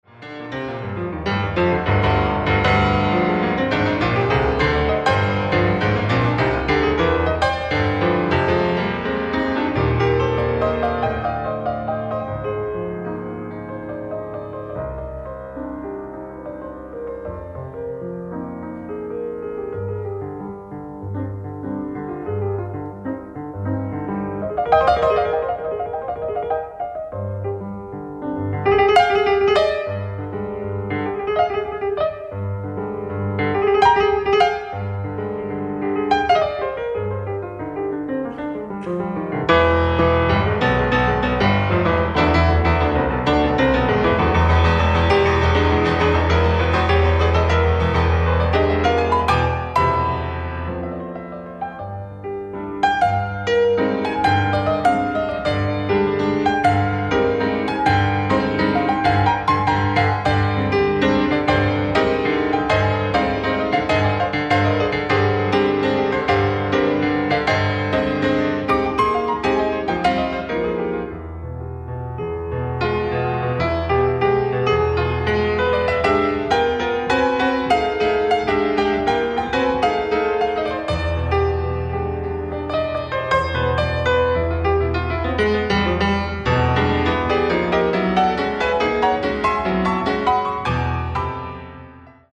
ライブ・アット・ブレーメン、ドイツ
※試聴用に実際より音質を落としています。